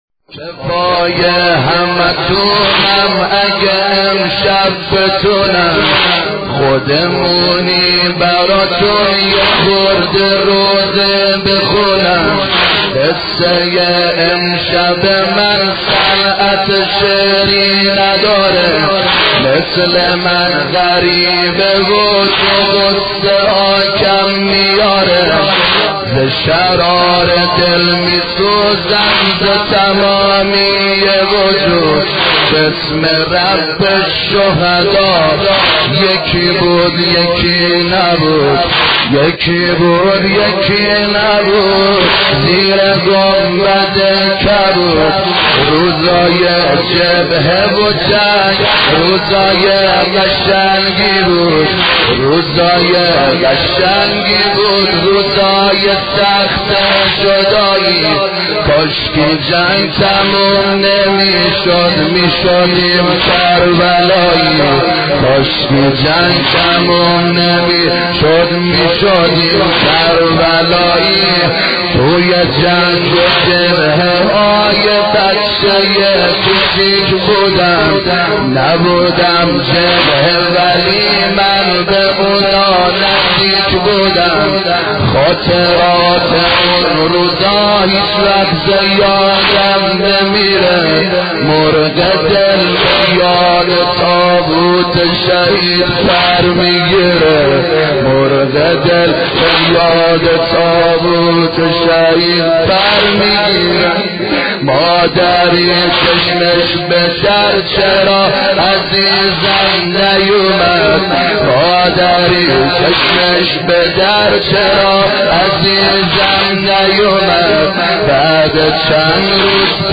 مداحی
maddahi-163.mp3